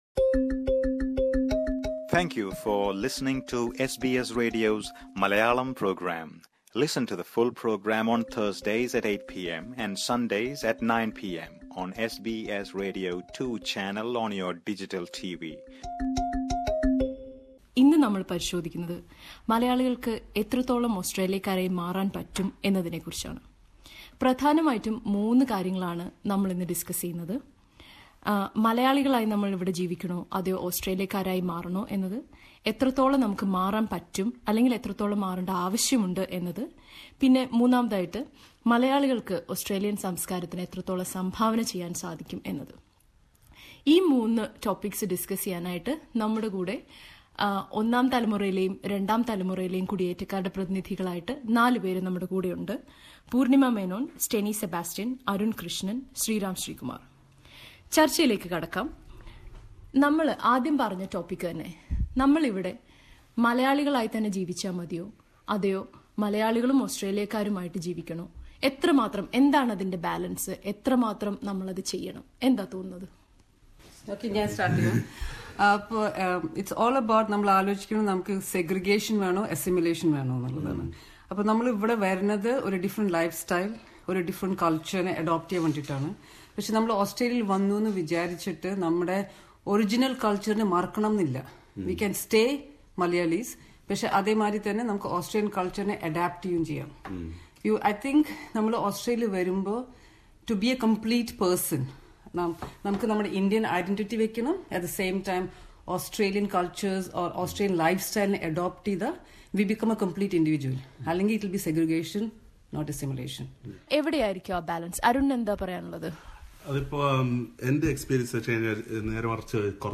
ഓസ്ട്രേലിയൻ മലയാളികൾ നേരിടുന്ന സാംസ്കാരിക വിഷയങ്ങളെക്കുറിച്ചുള്ള എസ് ബി എസ് മലയാളം പരന്പരയുടെ നാലാം ഭാഗത്തിൽ, നമ്മൾ എത്രത്തോളം ഓസ്ട്രേലിയക്കാരായി മാറുന്നുണ്ട് എന്ന വിഷയമാണ് പരിശോധിക്കുന്നത്. ഓസ്ട്രേലിയൻ സംസ്കാരത്തിൽ നിന്ന് എന്തു മൂല്യങ്ങളാണ് മലയാളികൾ സ്വീകരിക്കുന്നത്, എത്രത്തോളം മൂല്യങ്ങൾ തിരിച്ചുനൽകാൻ നമുക്ക് കഴിയുന്നു, എവിടെയാണ് മലയാളീസംസ്കാരത്തിൻറെയും ഓസീ സംസ്കാരത്തിൻറെയും അതിരുകൾ തുടങ്ങിയ വിഷയങ്ങളെക്കുറിച്ച് രണ്ടു തലമുറ മലയാളികളുൾപ്പെടുന്ന ചർച്ച കേൾക്കാം...